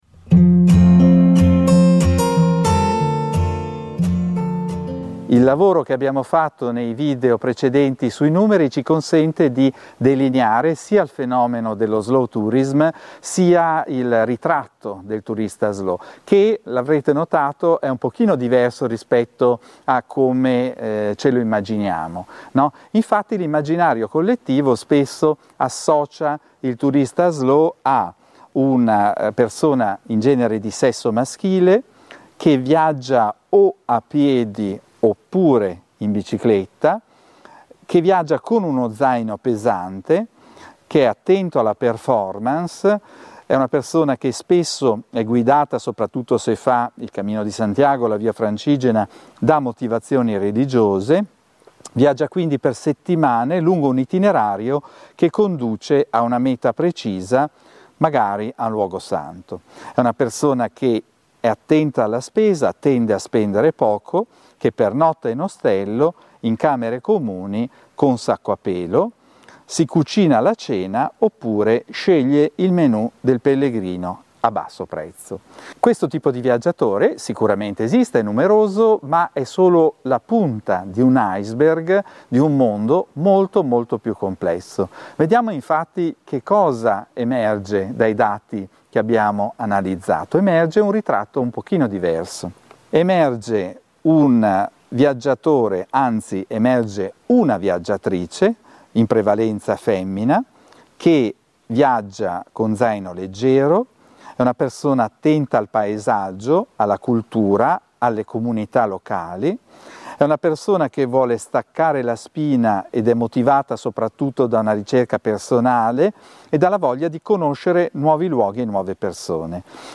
In questa lezione cerchiamo di sfatare i luoghi comuni, secondo i quali il viaggiatore a piedi o in bicicletta è una persona attenta alla spesa e orientata alla meta.